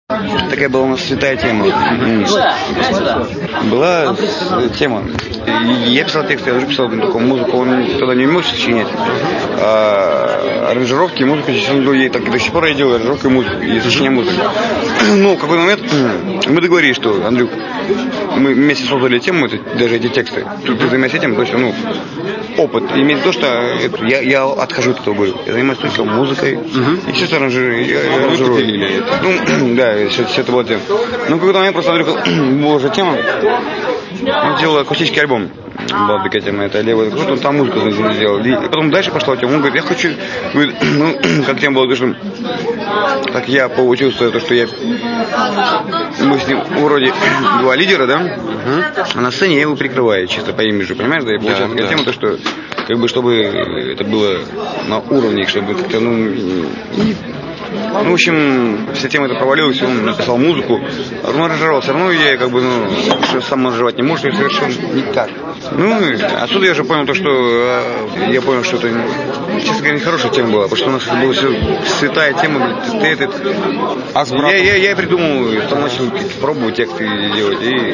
Интервью с Михаилом Горшеневым